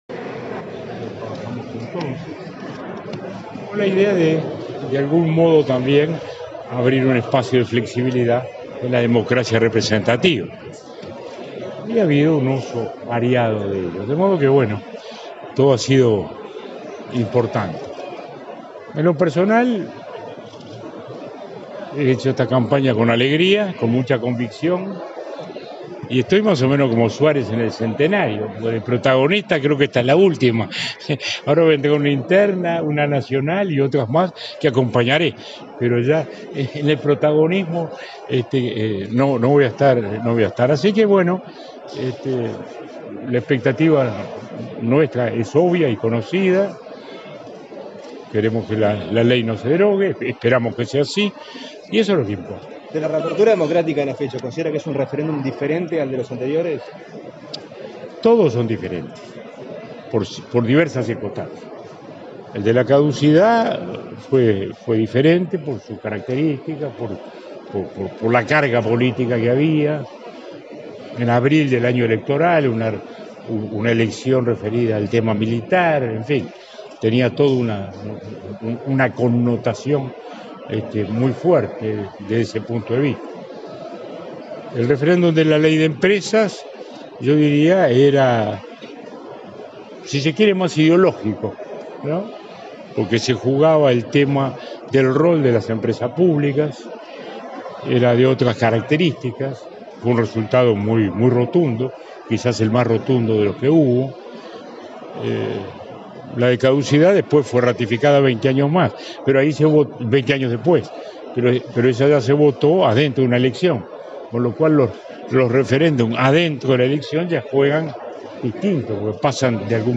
El expresidente Julio María Sanguinetti dijo que esta campaña del referéndum sobre la LUC «es la última» que vivió como «protagonista», en rueda de prensa luego de votar en el atrio de la Intendencia de Montevideo.